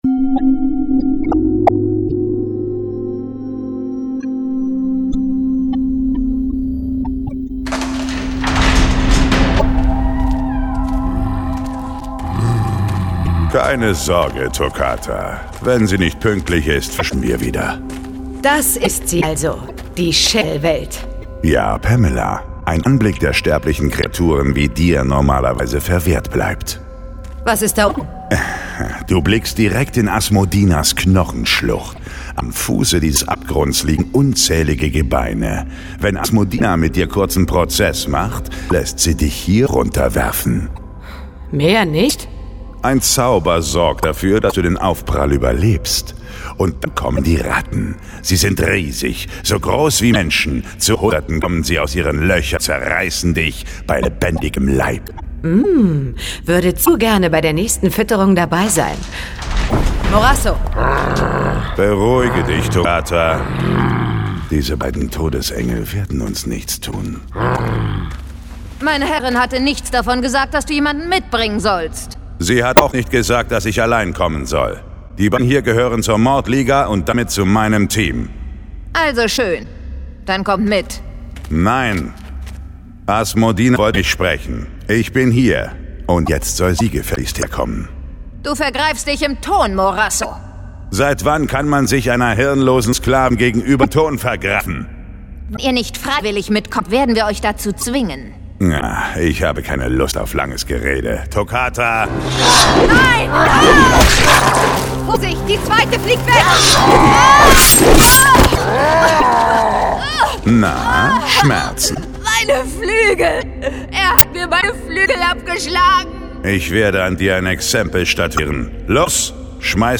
John Sinclair - Folge 46 Myxins Entführung. Hörspiel.